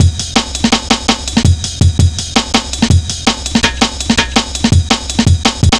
Index of /90_sSampleCDs/Zero-G - Total Drum Bass/Drumloops - 3/track 45 (165bpm)